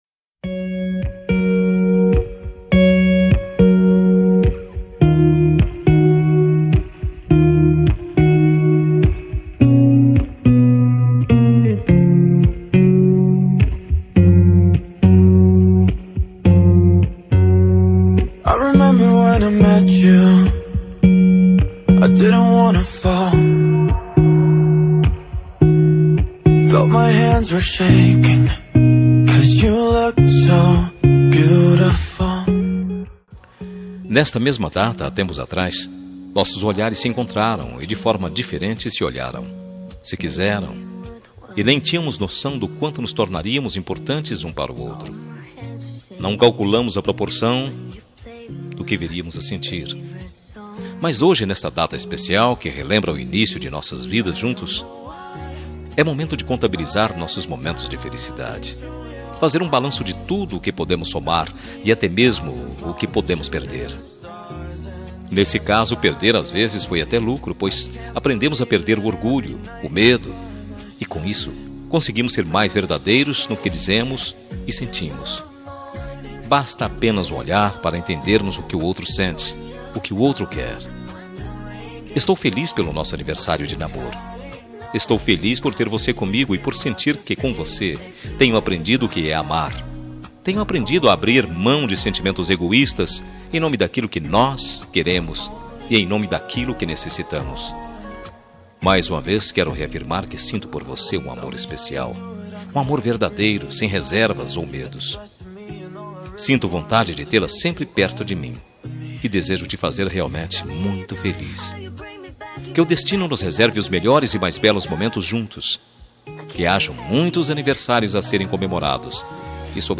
Telemensagem Aniversário de Namoro – Voz Masculina – Cód: 8100 – Linda.